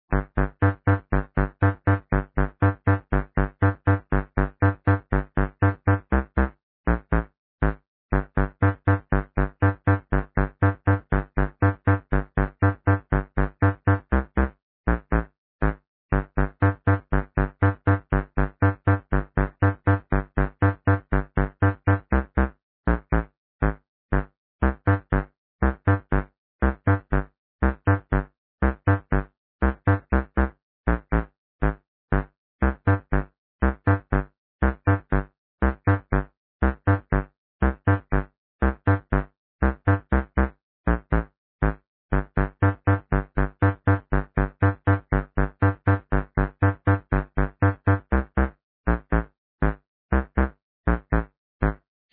I have created a sample of this old house song.